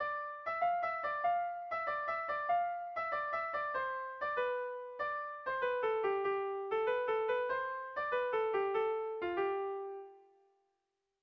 Irrizkoa
Zuberoa < Euskal Herria
AB